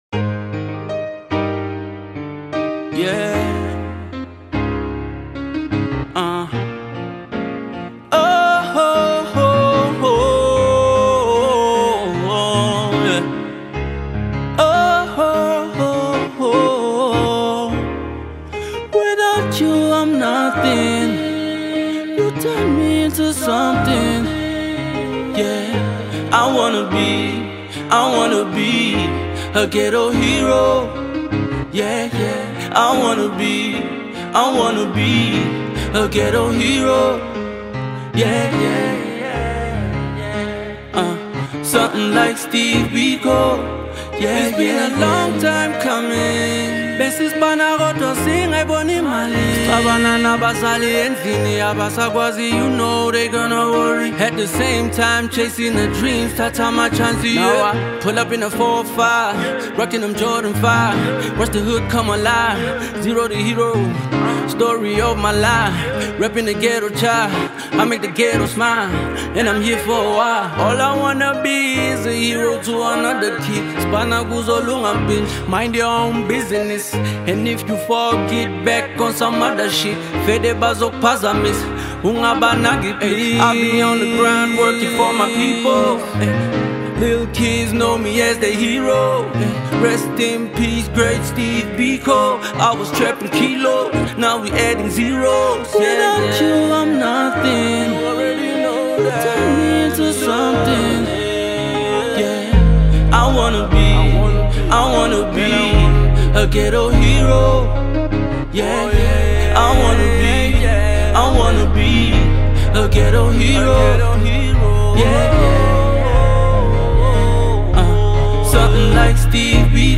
South African Rap god